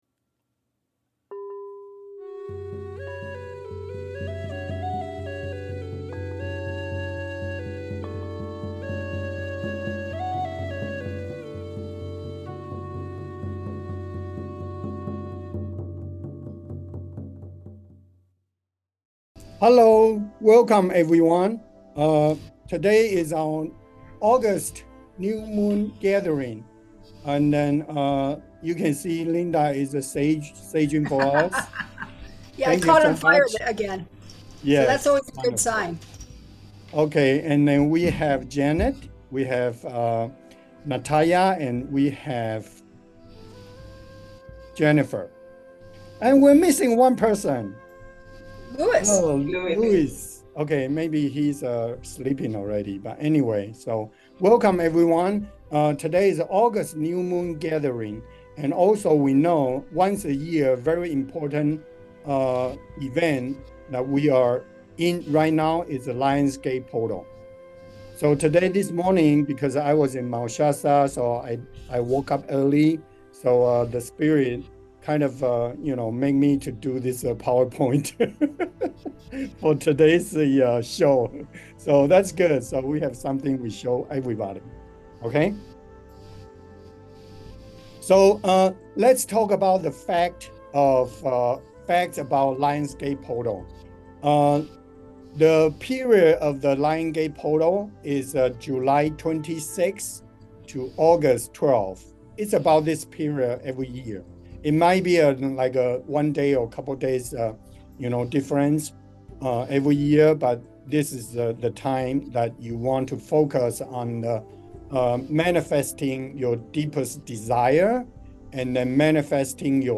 Mt Shasta Journey - 2024 Earth Guardian Meditation Group - Sunday, August 4, New Moon Gathering
Lion's Gate Portal with a guided meditation to connect the power of manifestation